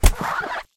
Spider_die3.ogg